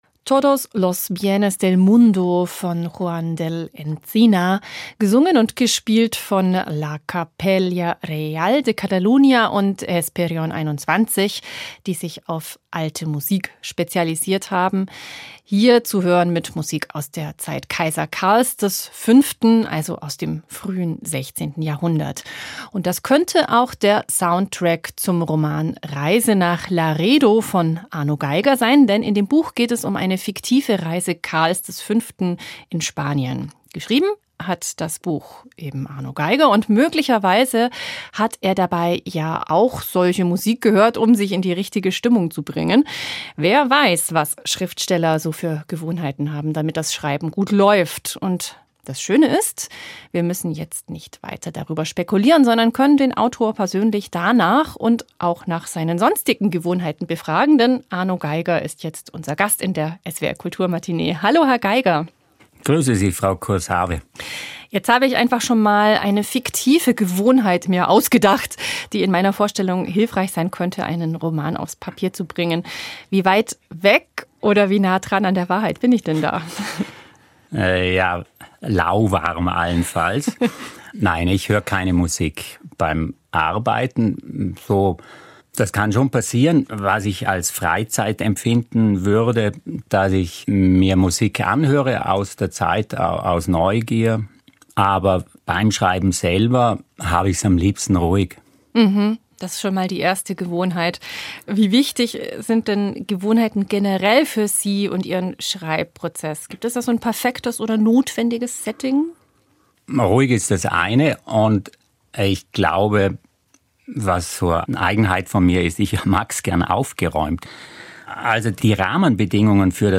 Der Schriftsteller Arno Geiger erzählt, wie er schreibend versucht sich aus dem Klammergriff des Altbewährten zu befreien. Interview